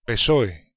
Partai Buruh Sosialis Spanyol (bahasa Spanyol: Partido Socialista Obrero Español [paɾˈtiðo soθjaˈlista oβɾeɾo espaˈɲol] ( simak); biasa disingkat PSOE [peˈsoe] (
Es_psoe_001.ogg